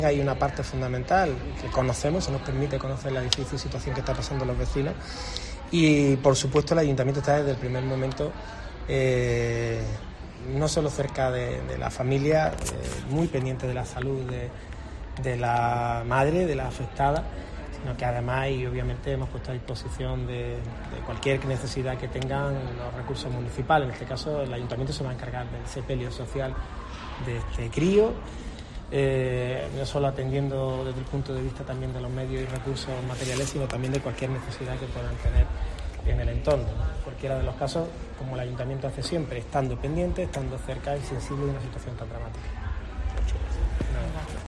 En declaraciones a los periodistas, Cuenca ha indicado que el consistorio se ha puesto "desde el primer momento" a disposición de la familia y los vecinos "desde el dolor", en un entorno que ha señalado que para él personalmente es "muy cercano".